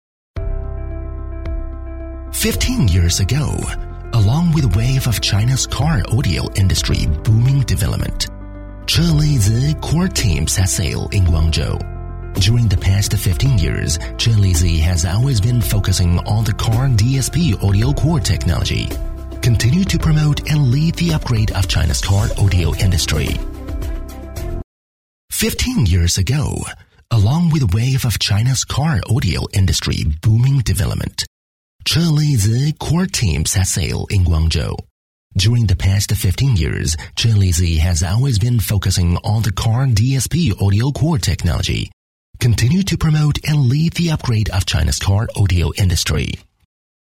【专题】美式 专题 年轻音色 科技感
【专题】美式 专题 年轻音色 科技感.mp3